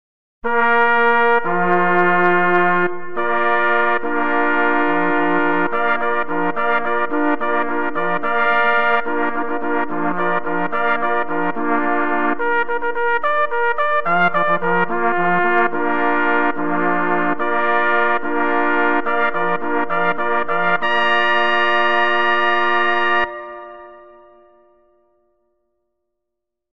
Categorie Ensembles
Subcategorie Hoorns
Bezetting 4 Jagdhörner